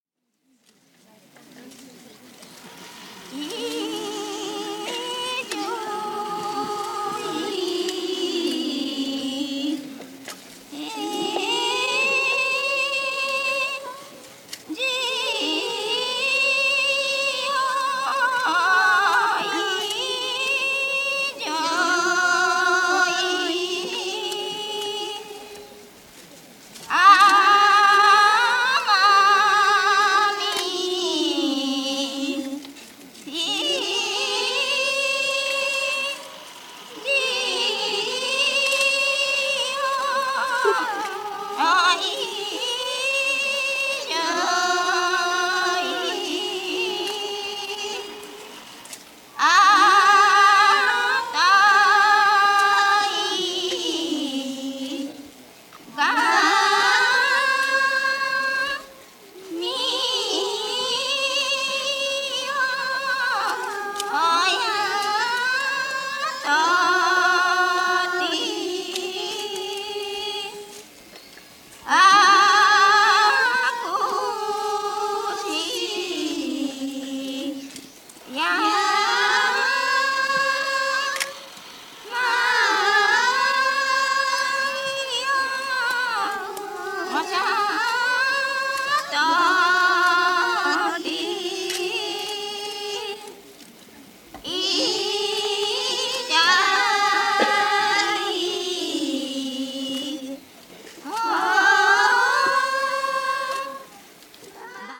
凄い！沖縄本島東、久高島で“ノロ”と呼ばれる神女たちが行う祭祀、“イザイホー”の音源集！
12年に一度行われる久高島神行事“イザイホー”は、1978年を最後に途絶えてしまっている訳ですが、本作はその祭祀の模様を楽しめる素晴らしい音源集となっています！ハーモニーと声の掛け合いで織りなす神謡の数々には圧倒的なパワーが漲っていますね！神々しい空間が産み出されていて、本当に神々が降りてきているような何とも言えない風合いが非常に魅力的ですよ！時間も空間も別次元にトリップしているような圧倒的で濃厚な世界が繰り広げられています！